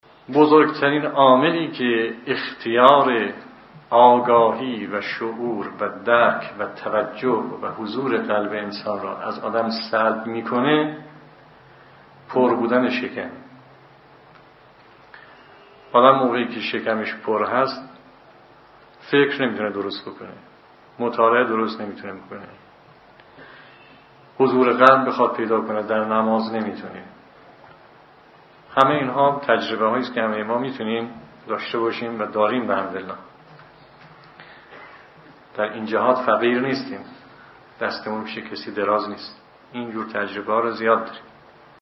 سخنرانی نماز